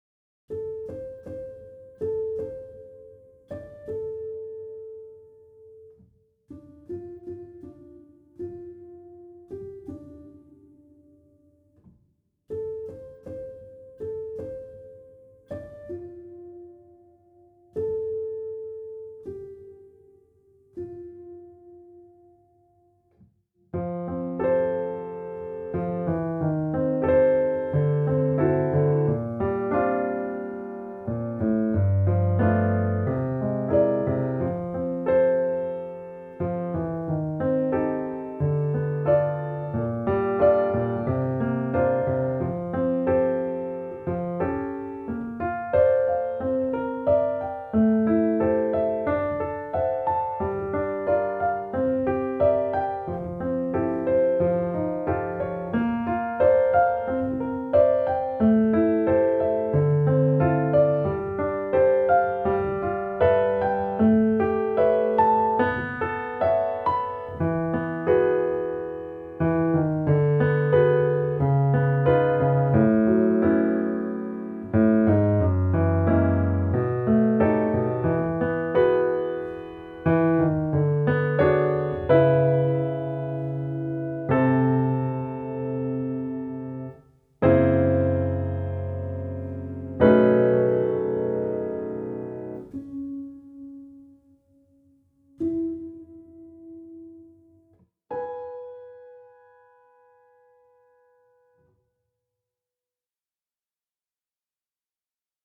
Piano Audio